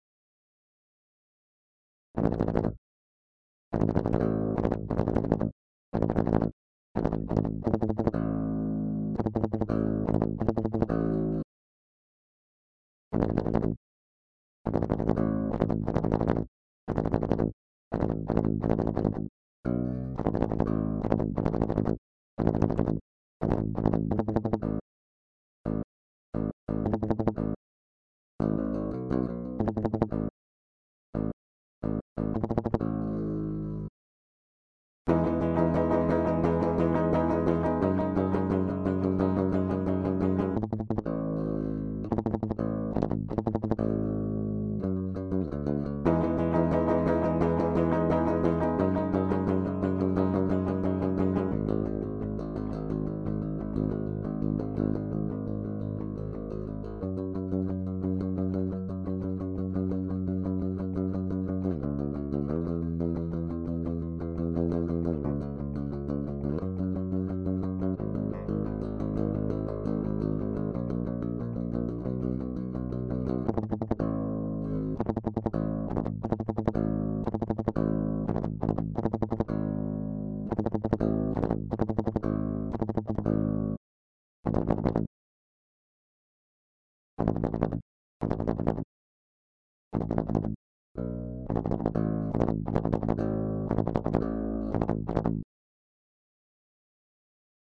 Ich benutze die Radial J48 DI Box.